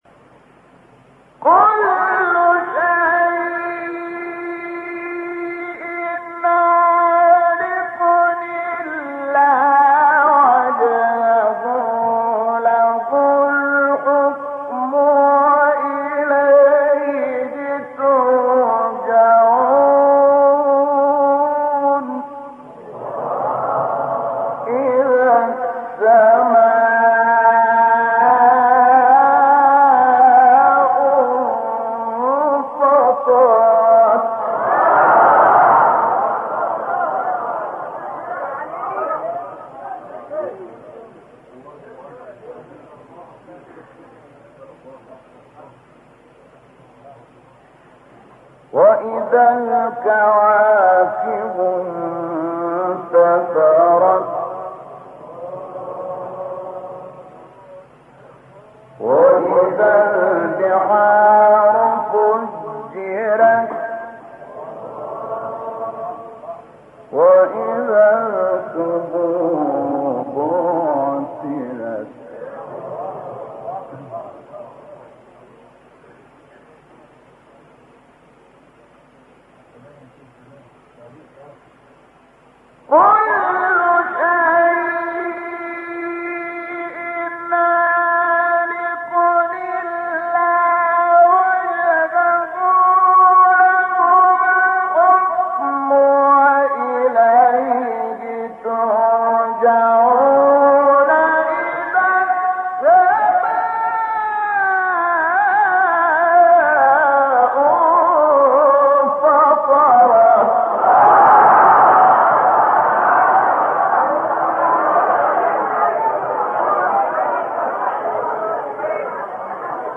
سوره : قصص – انفطار آیه: (88)- (1-8) استاد : شحات محمد انور مقام : سه گاه قبلی بعدی